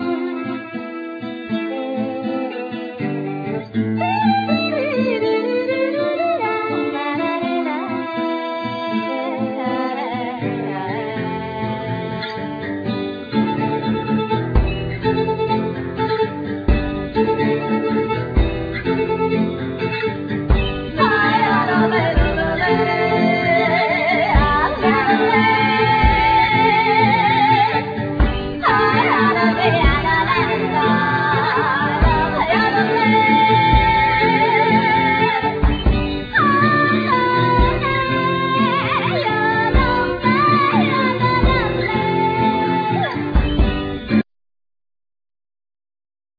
Violin,Kalimba,Percussion,Viola,Vocal,Africanlyra
Guitar,Sas,Vocal,Percussion,Mandolin
Piano,Vocal
Cello
Trumpet
Double bass